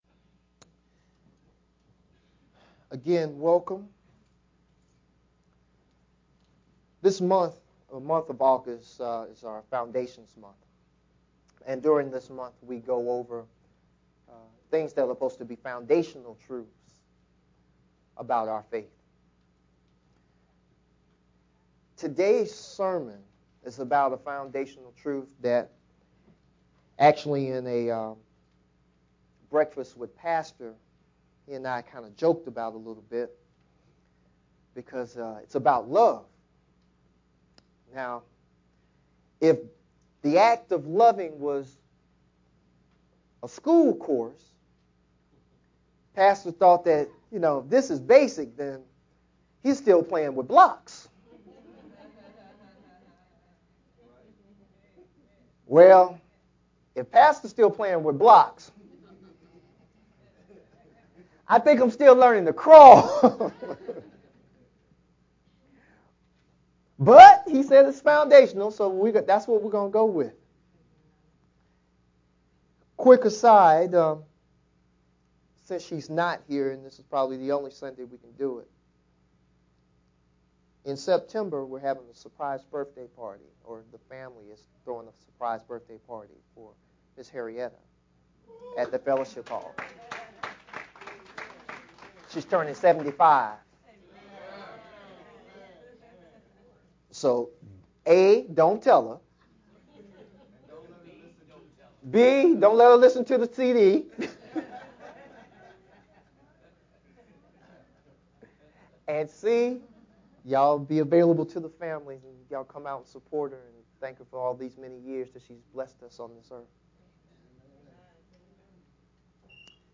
Sermon of 8/9/2015